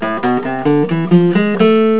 The harmonic minor scale contains the same half steps between notes 2 to 3 and notes 5 to 6, but also contains a half step between notes 7 and 8.
It is the same scale as the natural minor except for the raised 7th note of the scale, which makes the interval between notes 6 to 7 larger and notes 7 to 8 smaller.
ScaleHarm.au